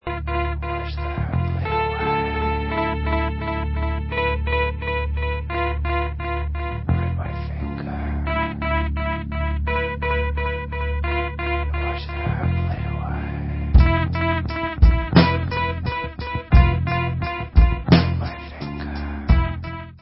Belgian metal/rock/core